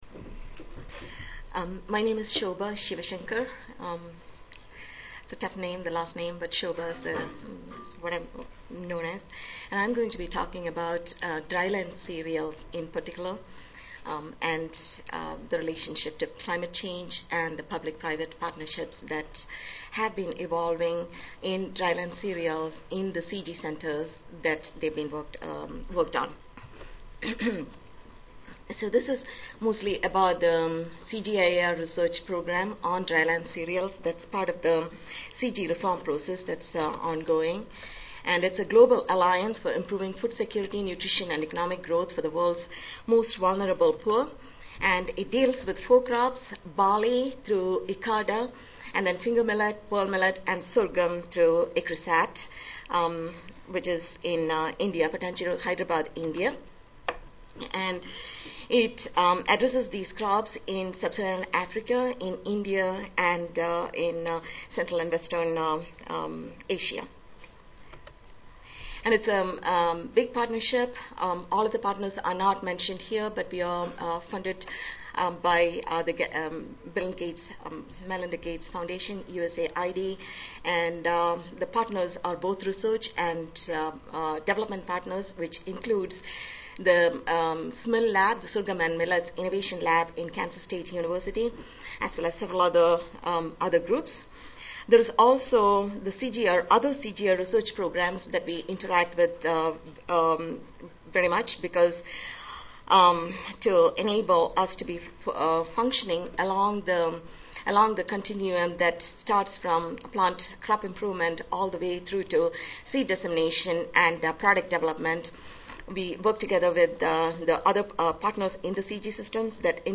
C02 Crop Physiology and Metabolism Session: Symposium--the Role of Public-Private Partnership to Increase the Speed with Which Agriculture Can Adapt to Climate Change (ASA, CSSA and SSSA International Annual Meetings)
ICRISAT Audio File Recorded Presentation